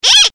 clock10.ogg